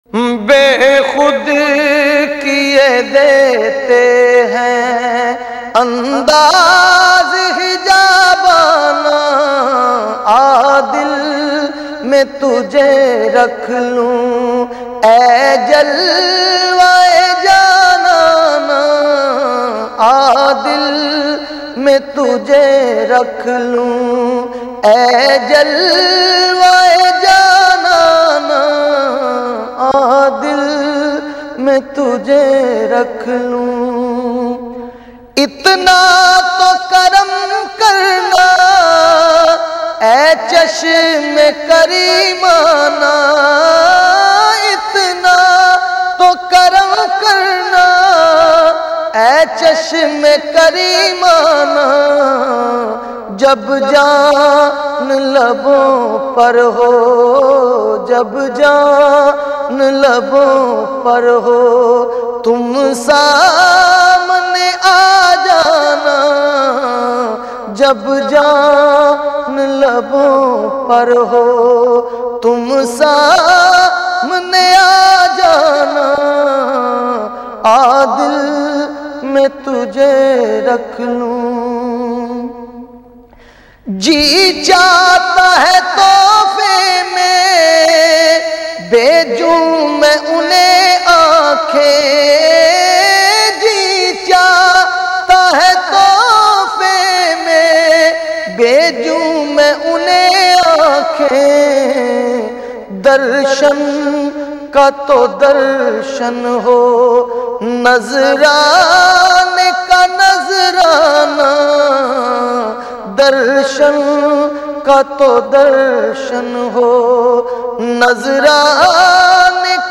Beautifull Naat
in best audio quality